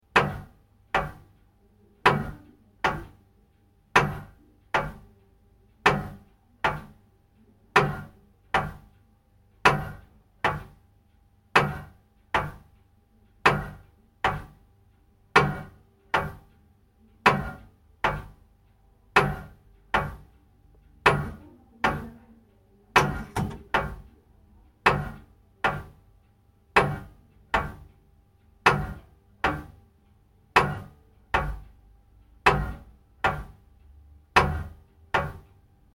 دانلود صدای ساعت 25 از ساعد نیوز با لینک مستقیم و کیفیت بالا
جلوه های صوتی
برچسب: دانلود آهنگ های افکت صوتی اشیاء دانلود آلبوم صدای ساعت از افکت صوتی اشیاء